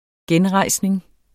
Udtale [ ˈgεnˌʁɑjˀsneŋ ]